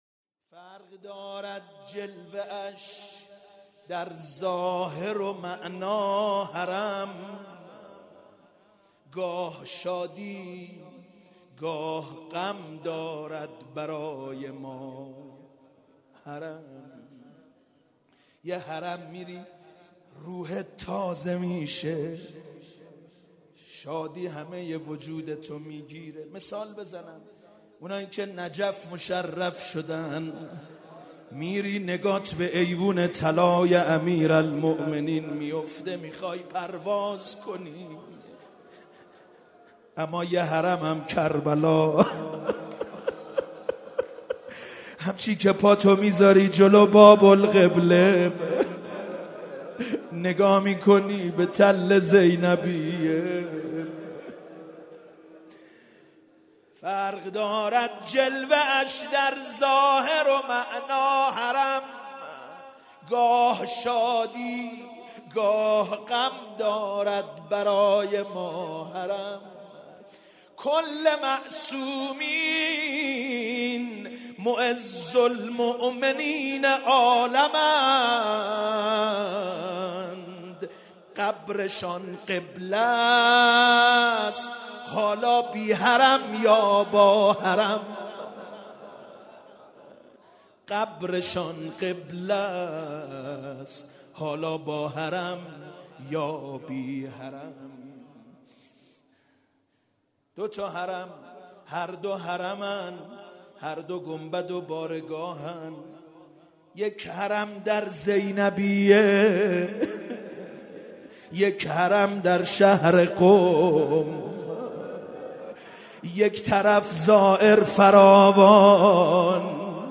8 شوال -روضه - فرق دارد جلوه اش در ظاهر و معنا حرم